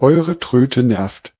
A free to use, offline working, high quality german TTS voice should be available for every project without any license struggling.
Added silero models to audio comparison